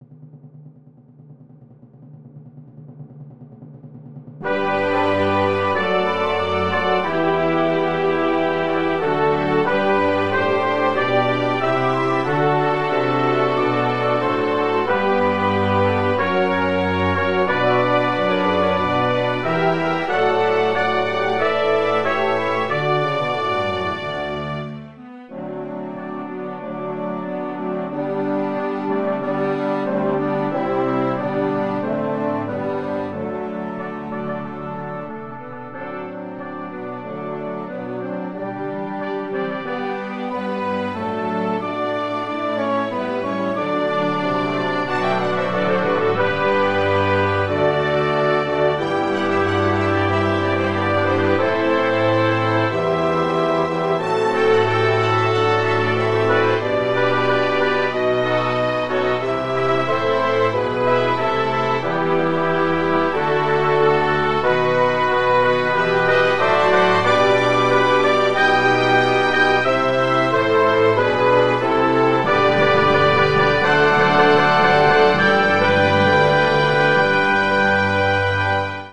Full Orchestra